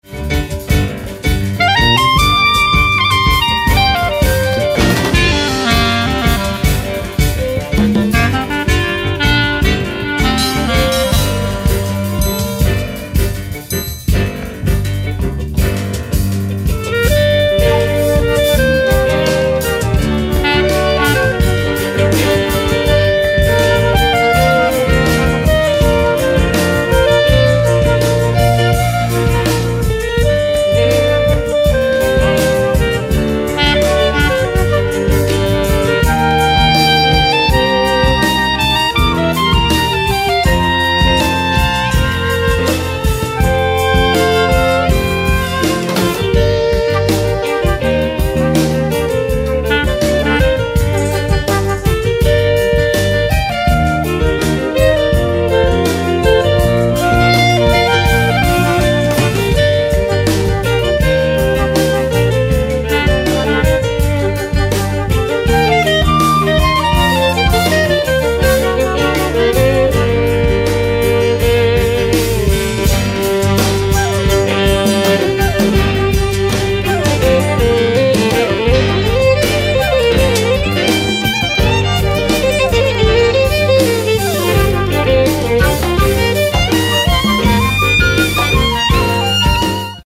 Live au FMPM 2006: